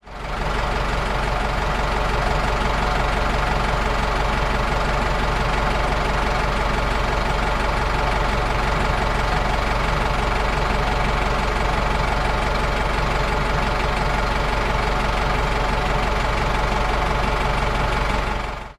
in_tractor_idle_01_hpx
Bobcat starts and operates then shuts off. Good panning. Diesel tractor idles. Diesel, Machinery Tractor Engine, Tractor